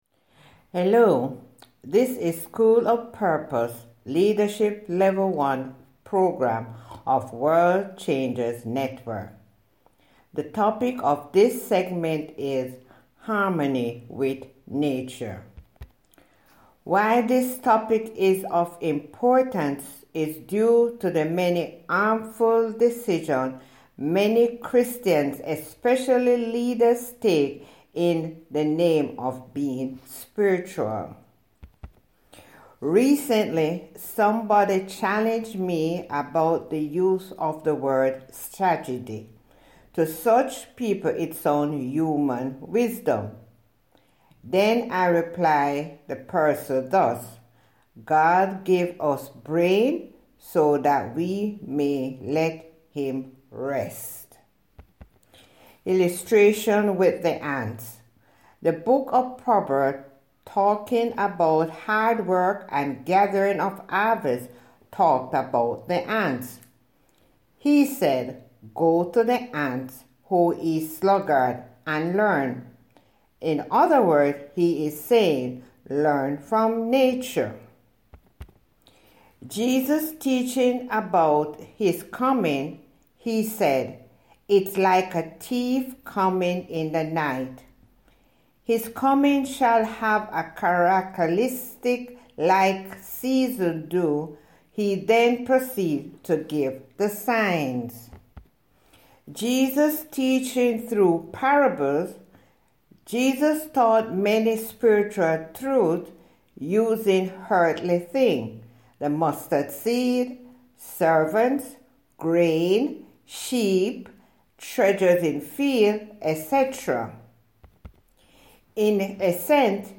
Listen to Human Voice Reading Here: